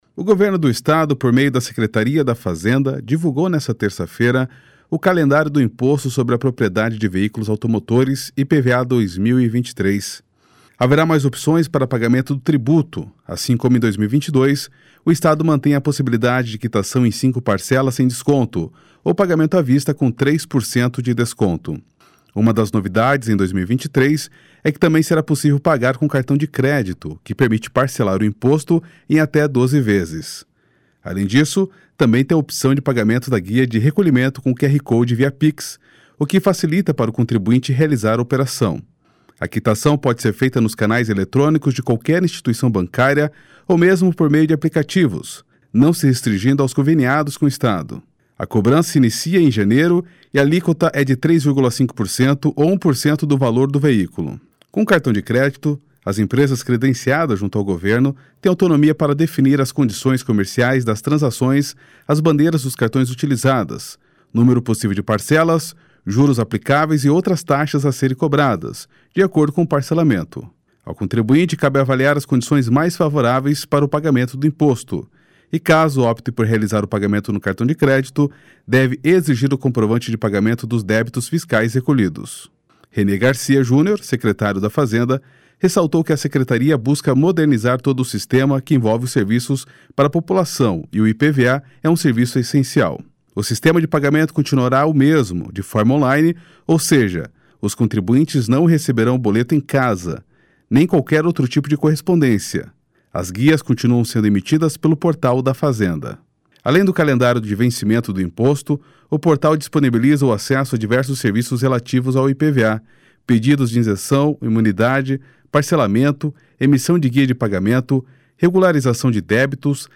Renê Garcia Junior, secretário da Fazenda, ressaltou que a Secretaria busca modernizar todo o sistema que envolve os serviços para a população e o IPVA é um serviço essencial.